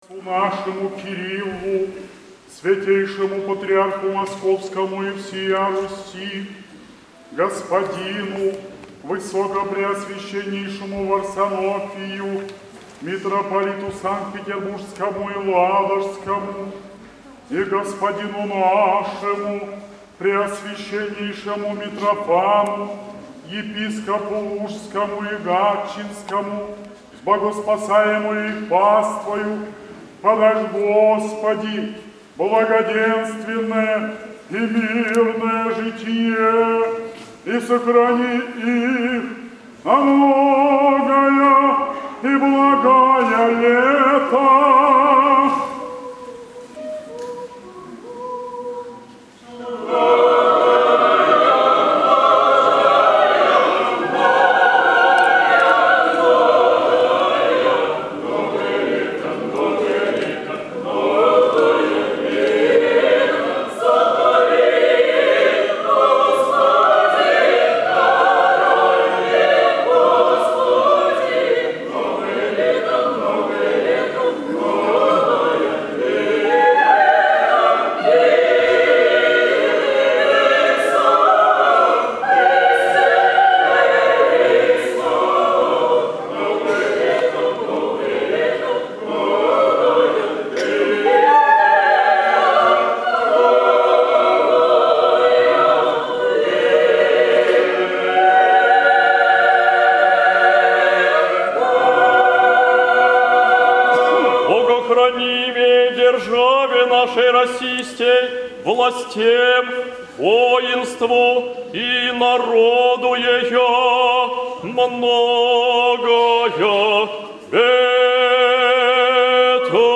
Возглашение многолетия. 04.11.2025